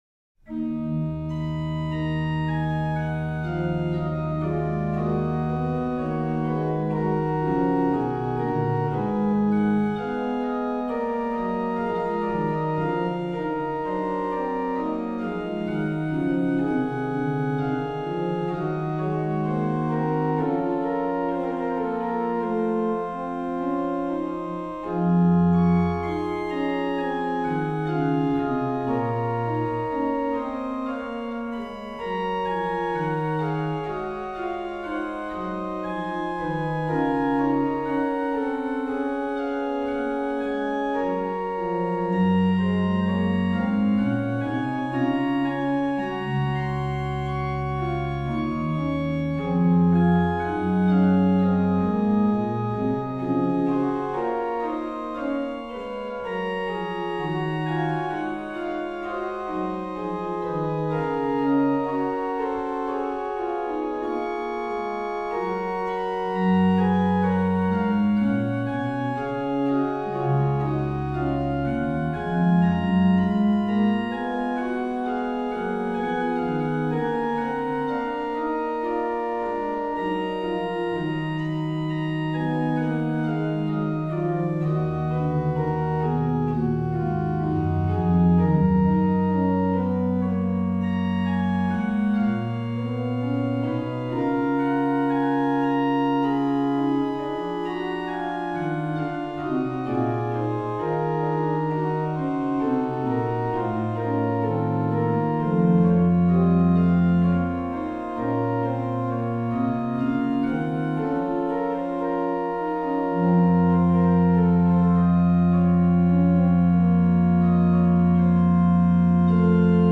Subtitle   Choral in Alto
Registration   MAN: Pr8
PED: Oct8